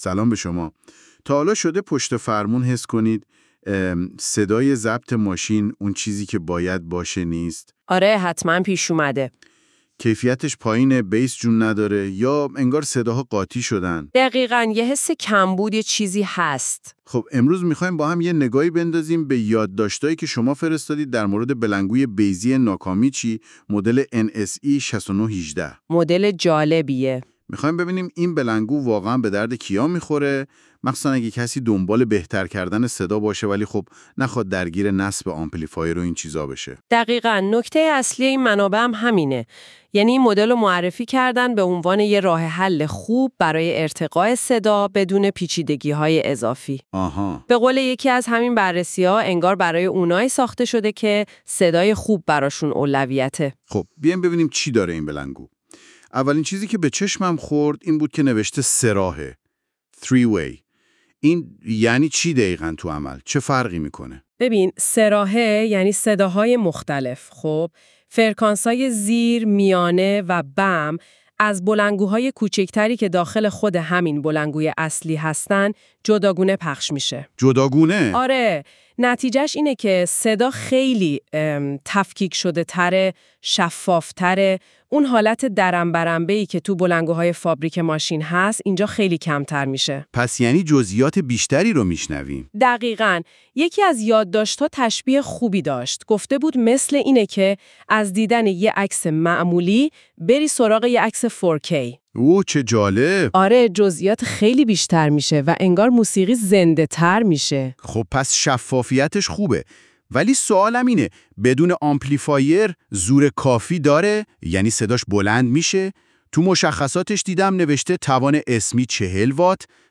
Nakamichi-NSE6918-Car-Speaker-Review.wav